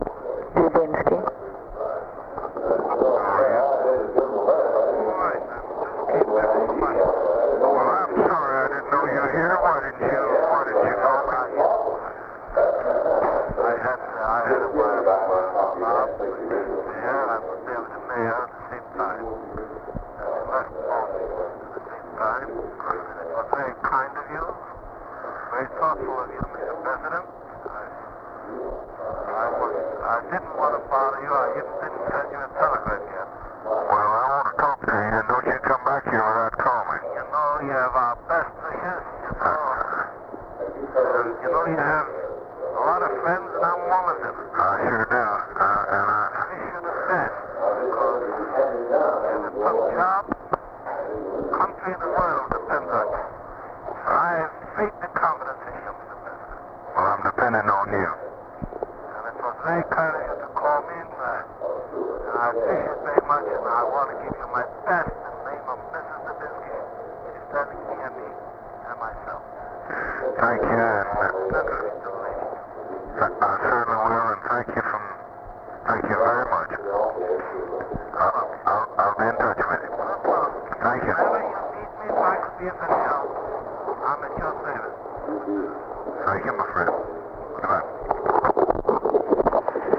Conversation with EDWARD KENNEDY, November 24, 1963
Secret White House Tapes